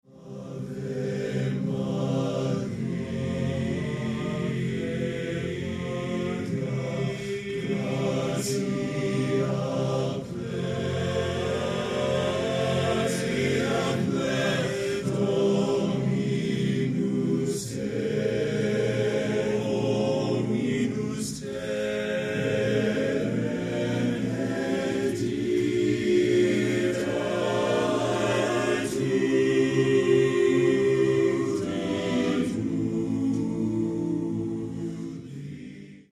You will savor the rich taste of four-part harmony.